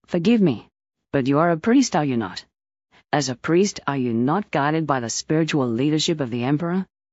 Add Kusari Mission Female Voice File